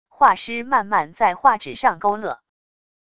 画师慢慢在画纸上勾勒wav音频